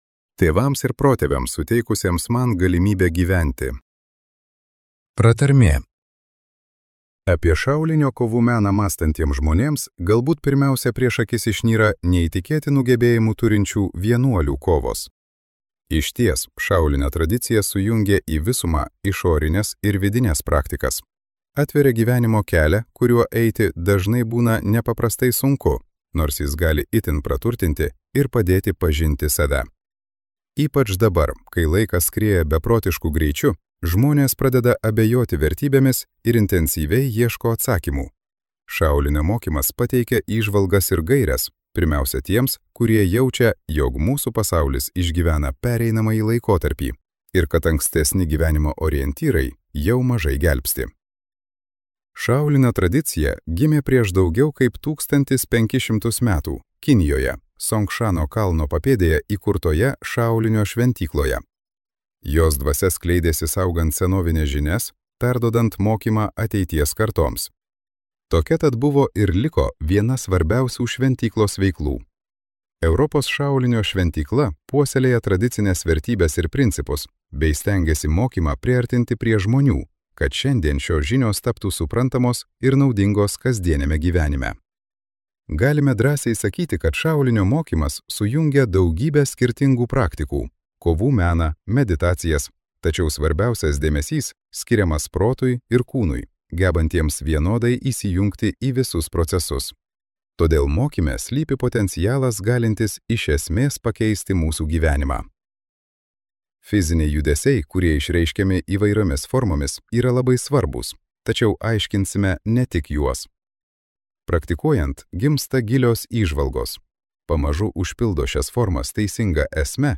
Skaityti ištrauką play 00:00 Share on Facebook Share on Twitter Share on Pinterest Audio Šaolinio dvasia.